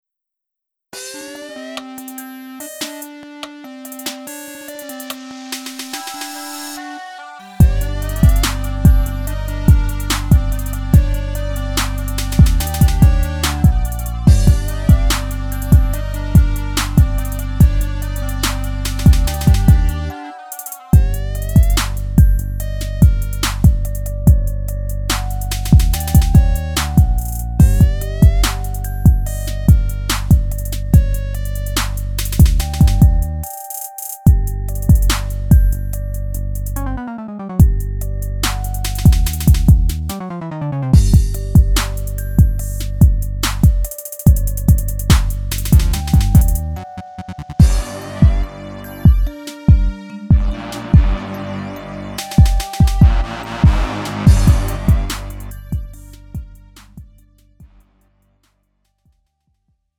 음정 -1키 2:39
장르 가요 구분 Lite MR